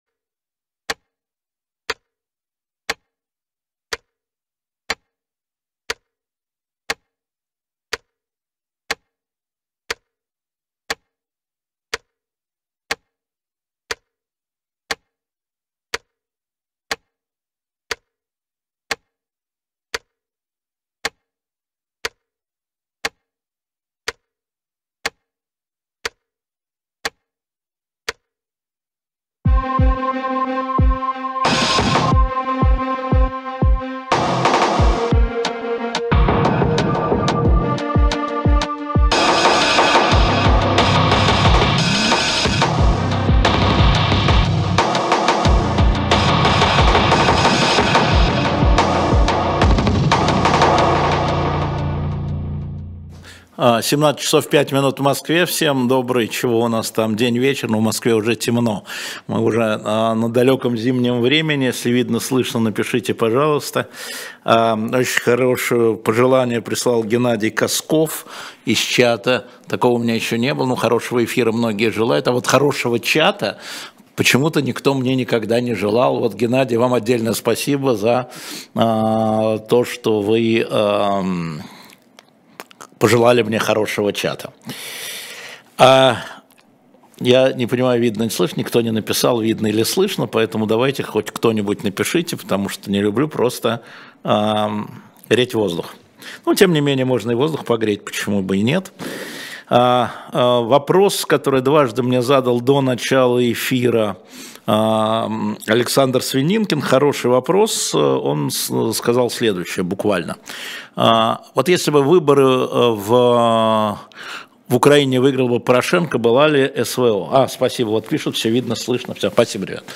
Алексей Венедиктов отвечает на ваши вопросы в прямом эфире